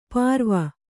♪ pārva